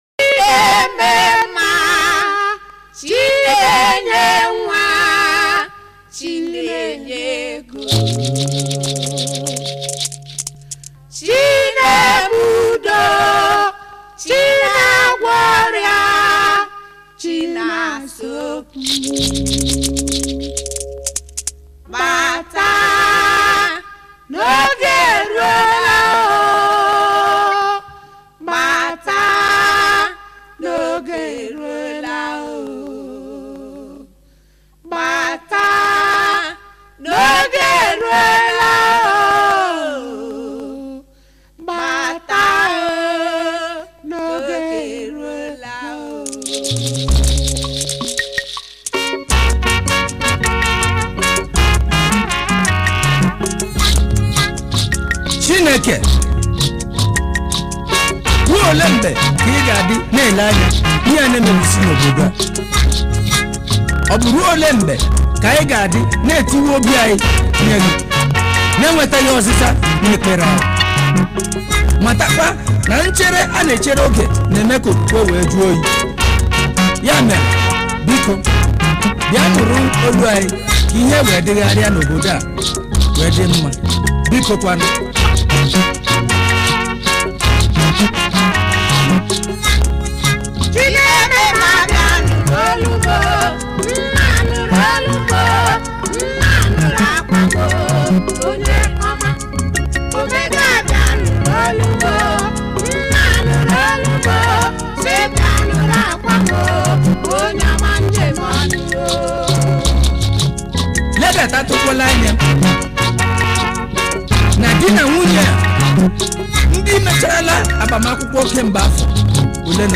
February 21, 2025 Publisher 01 Gospel 0